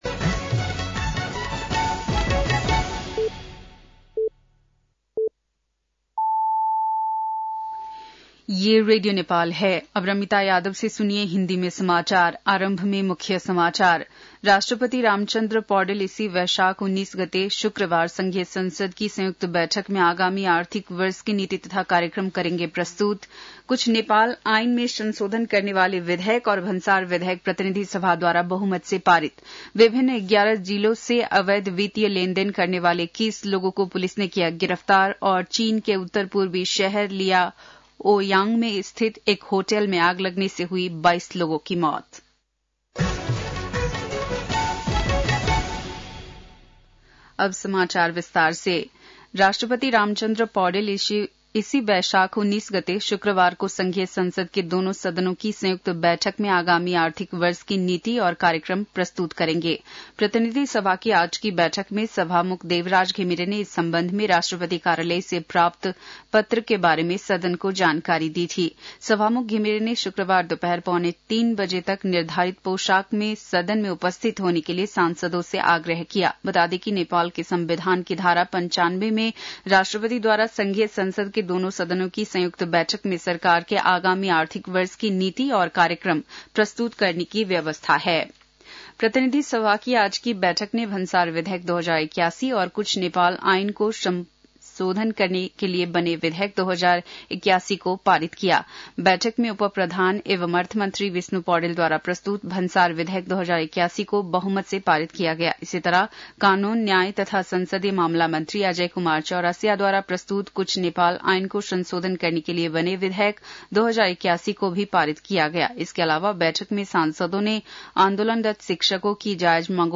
बेलुकी १० बजेको हिन्दी समाचार : १६ वैशाख , २०८२
10-pm-hindi-news-1-16.mp3